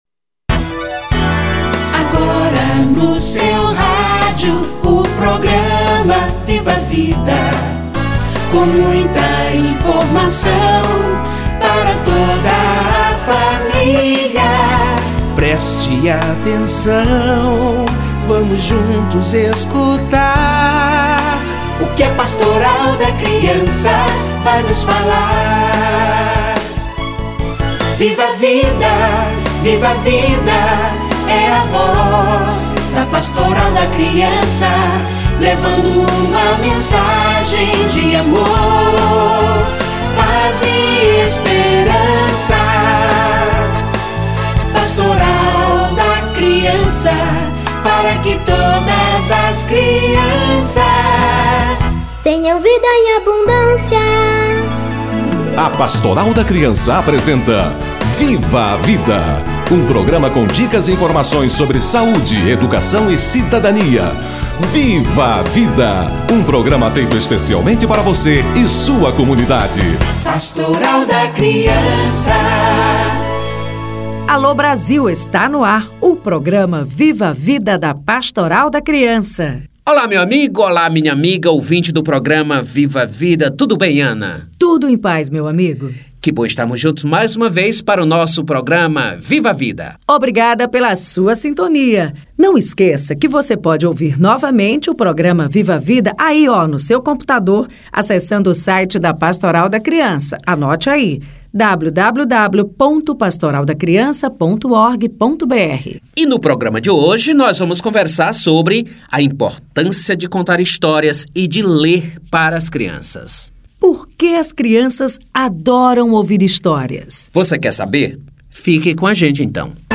Leitura para as crianças - Entrevista